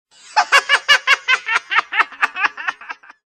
甲高い男性の笑い声